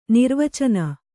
♪ nirvacana